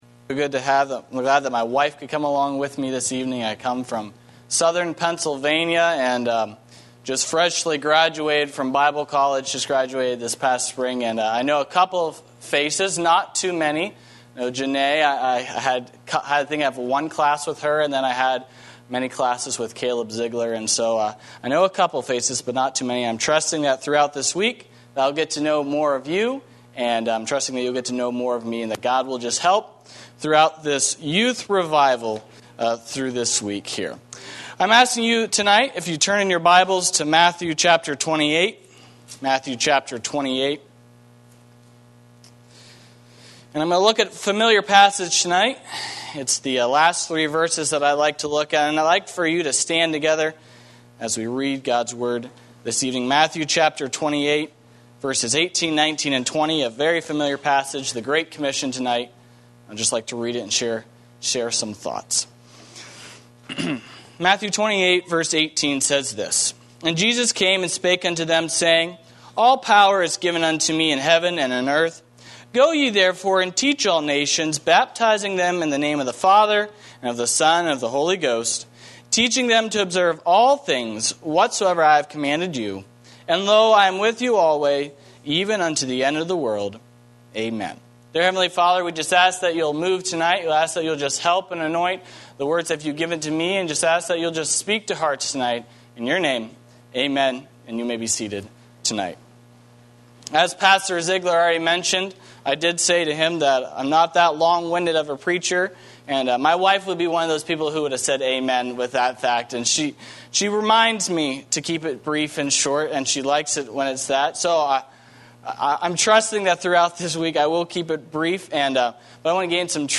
Series: Youth Revival 2020